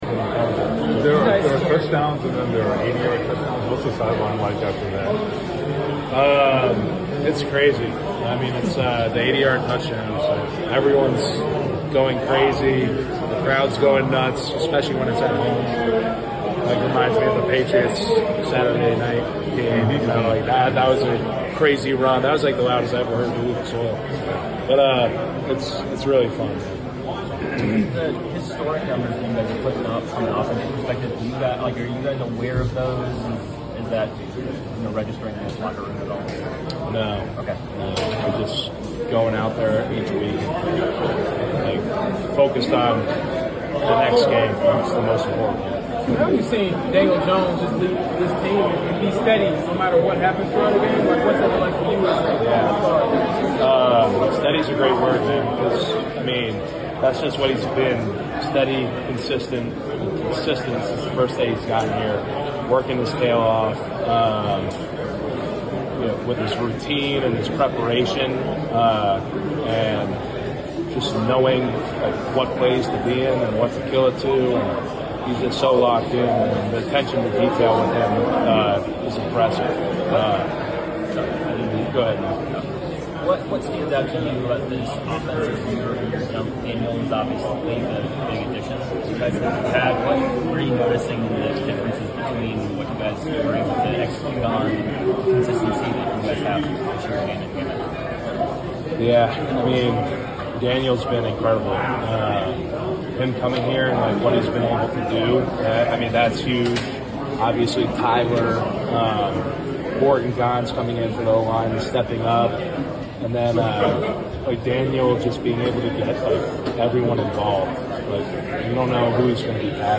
10-26-25 Indianapolis Colts Offensive Lineman Quenton Nelson Postgame Interview
Indianapolis Colts Offensive Lineman Quenton Nelson Postgame Interview after defeating the Tennessee Titans at Lucas Oil Stadium.